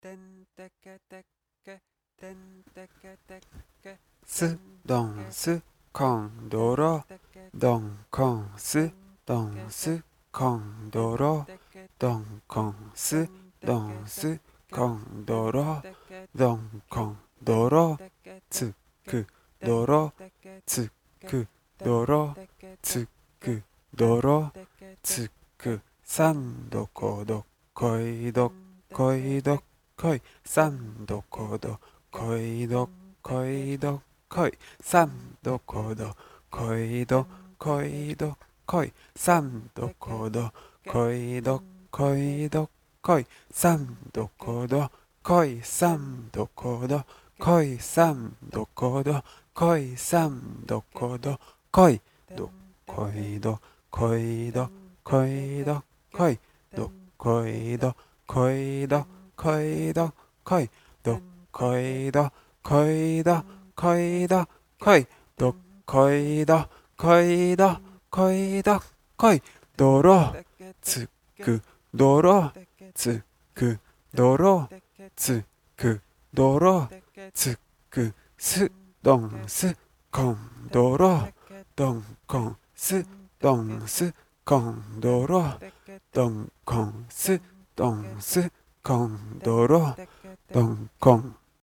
buchi-awase_wadaiko_shouga_zuerich.mp3